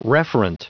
Prononciation du mot referent en anglais (fichier audio)
Prononciation du mot : referent